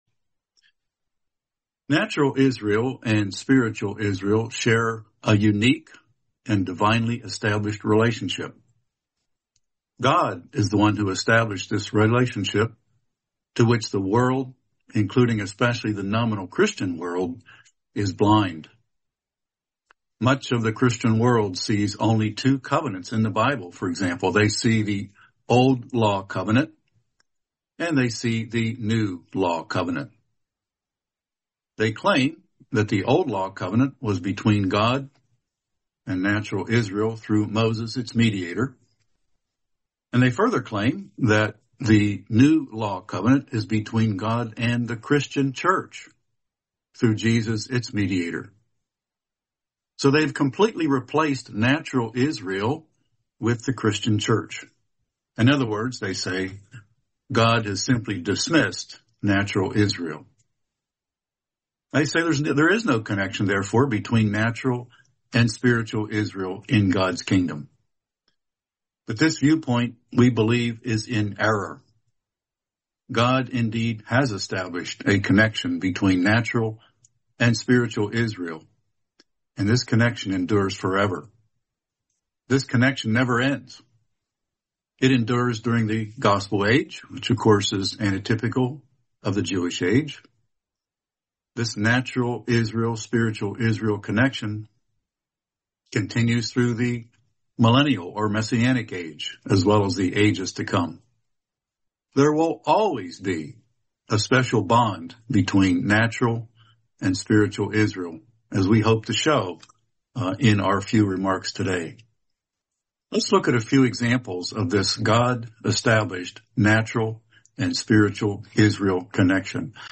Series: 2026 ABSCO Convention